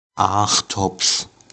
The Aachtopf (German: [ˈaːxtɔpf]
De-Aachtopf.oga.mp3